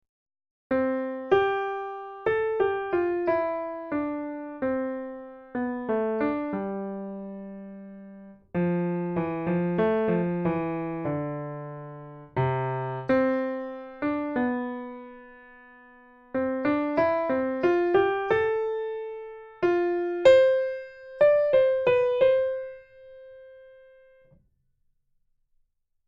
013 - L3 - lecture chantée - complet